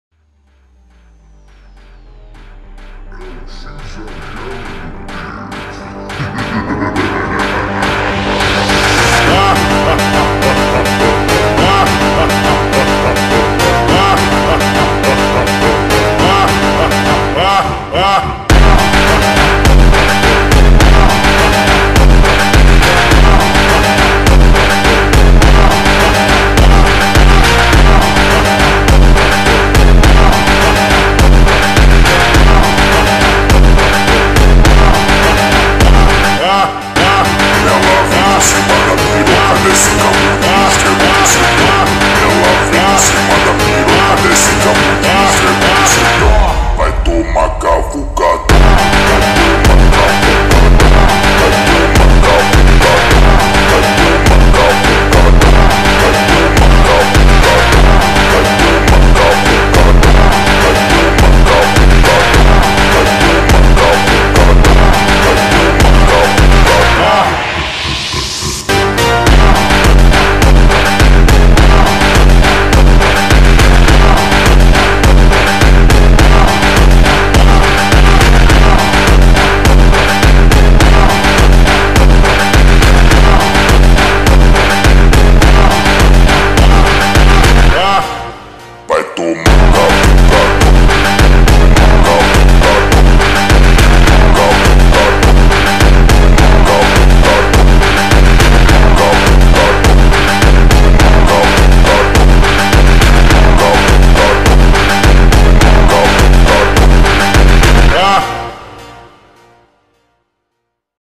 فانک خفن ورژن slowed
فانک